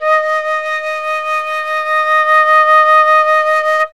51c-flt19-D#4.wav